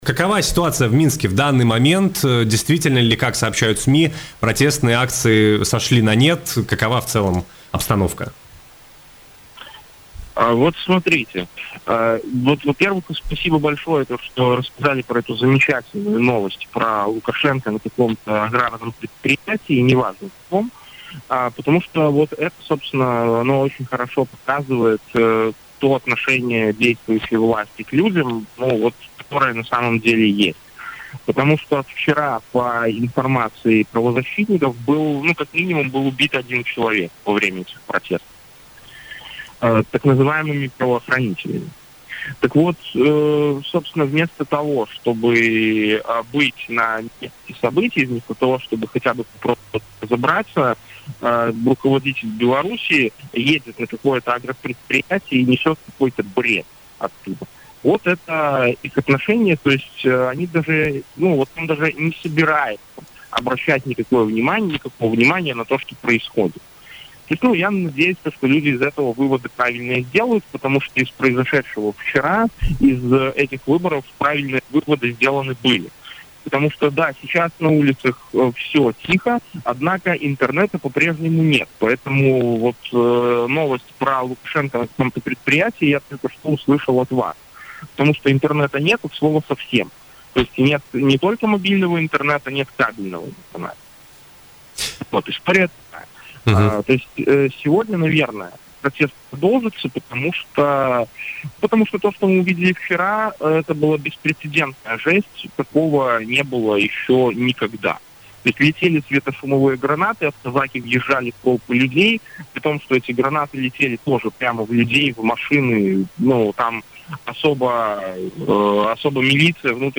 «Это беспрецедентная жесть. Интернет до сих пор не работает»: журналист из Беларуси о ситуации в стране